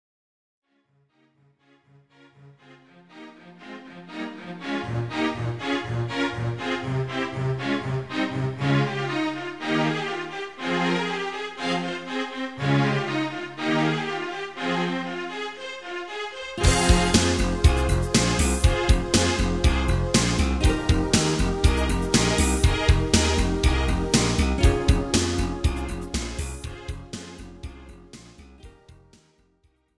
This is an instrumental backing track cover.
• Key – Fm
• With Backing Vocals
• With Fade